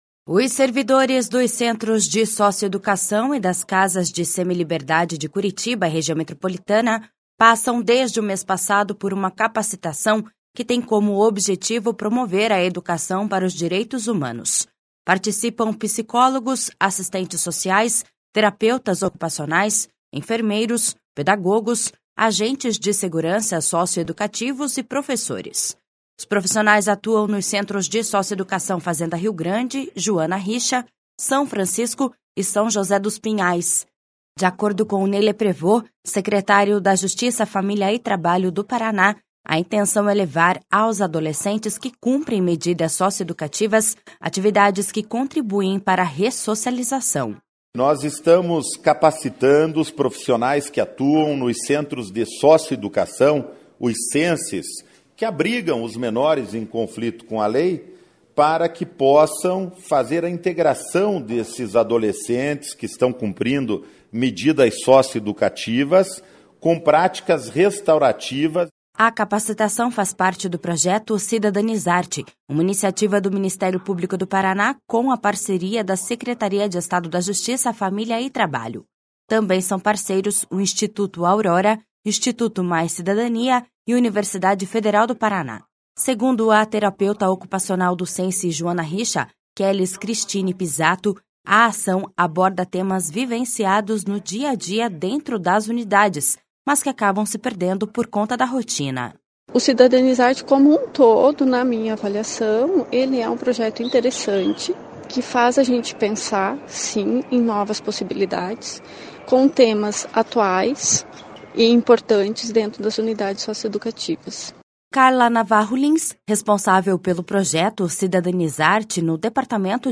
De acordo com Ney Leprevost, secretário da Justiça, Família e Trabalho do Paraná, a intenção é levar aos adolescentes que cumprem medidas socioeducativas atividades que contribuem para a ressocialização.// SONORA NEY LEPEREVOST.//